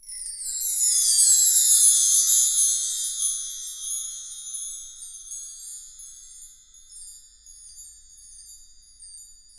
Index of /90_sSampleCDs/Roland LCDP03 Orchestral Perc/PRC_Wind Chimes1/PRC_W.Chime Down